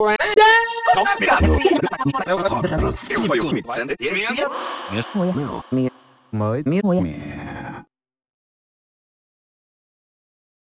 This is an example of a highly timecompressed